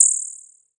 prcTTE47024techno.wav